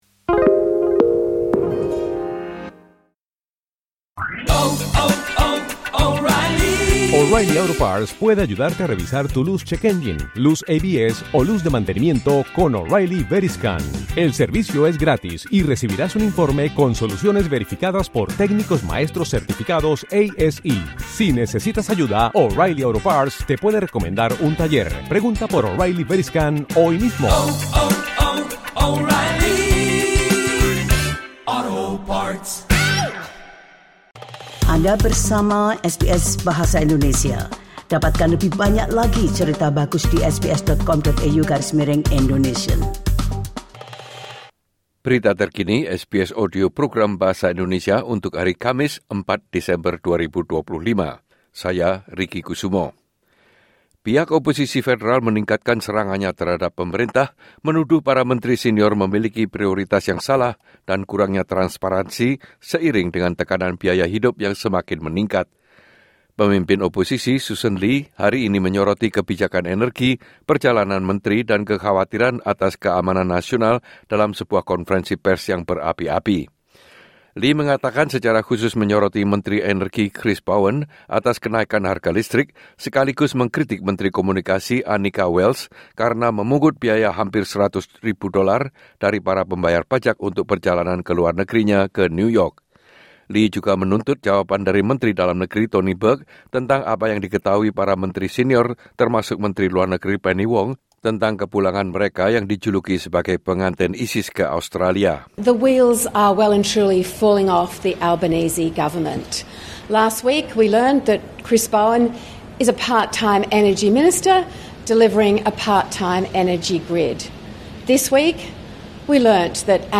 Berita terkini SBS Audio Program Bahasa Indonesia – 4 Desember 2025